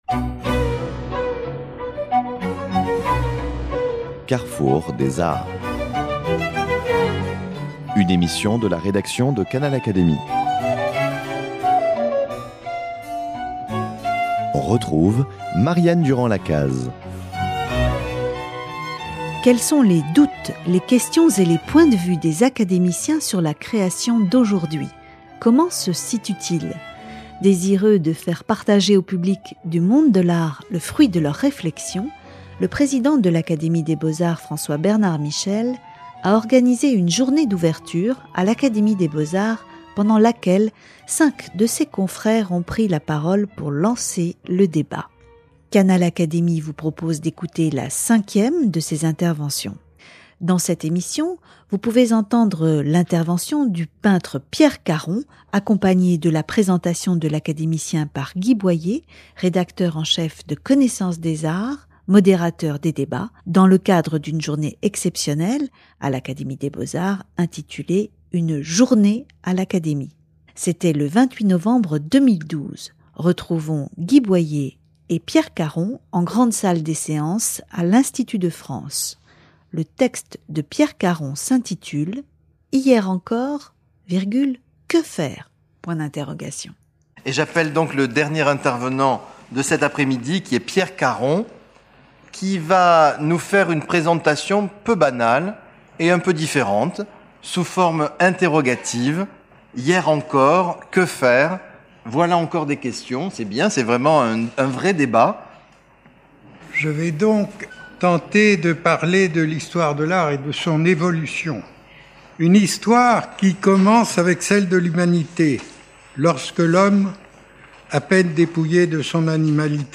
L'intervention de Pierre Carron s'est déroulée dans la grande salle des séances où se réunissent habituellement les membres de l'Académie des beaux-arts.